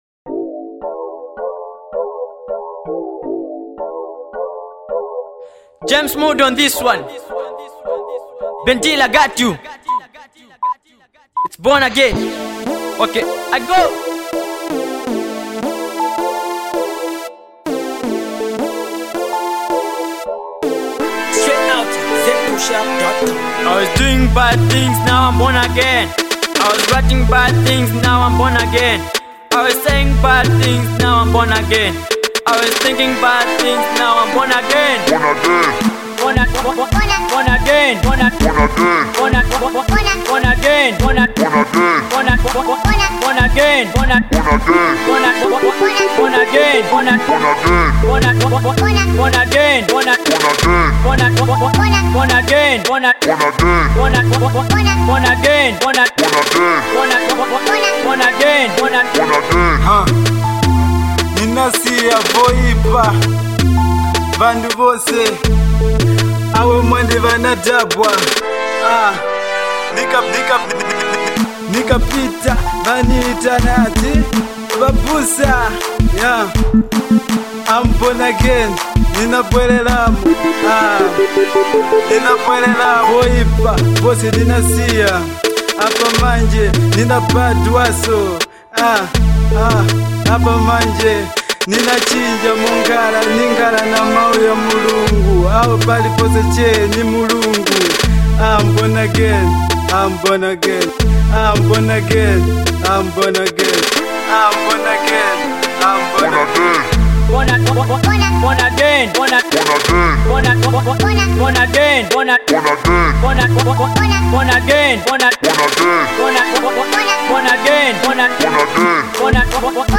Blazing Gospel joint